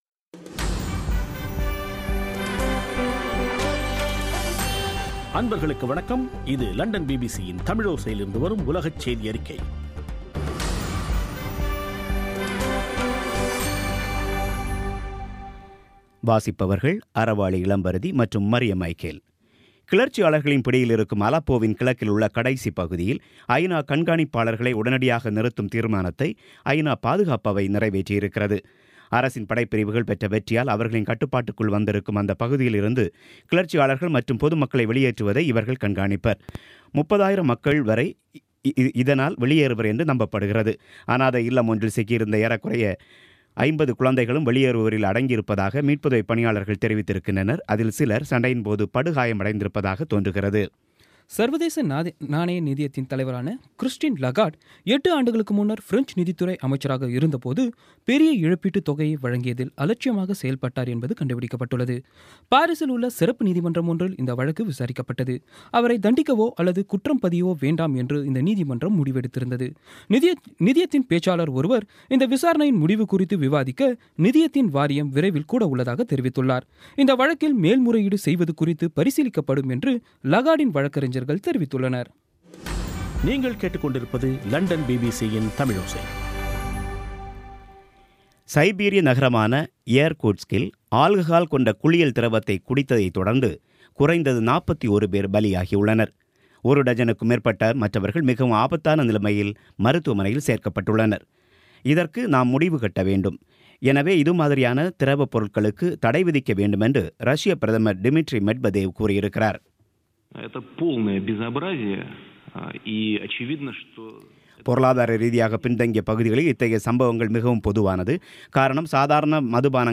பி பி சி தமிழோசை செய்தியறிக்கை (19/12/16)